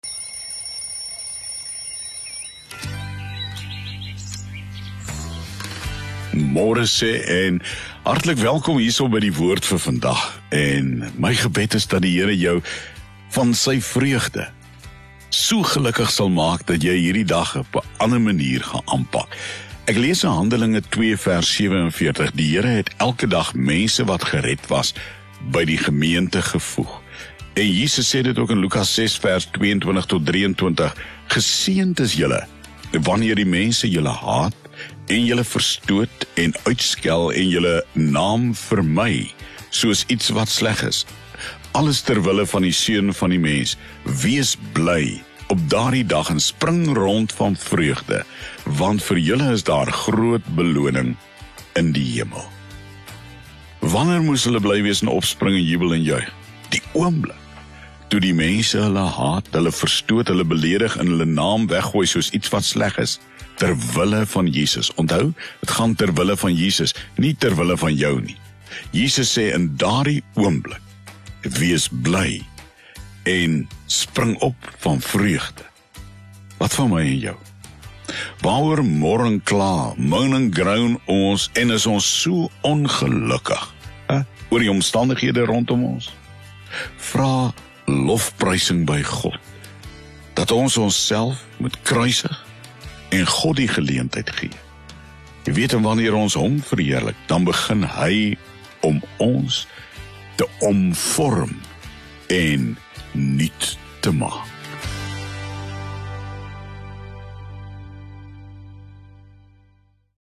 Gedagte vir die Dag soos gehoor op OFM. 26 September 2021